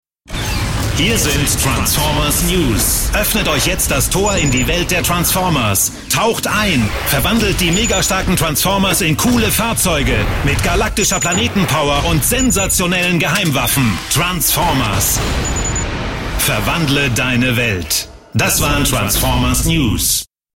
Sprecher deutsch.
Sprechprobe: Werbung (Muttersprache):
german voice over artist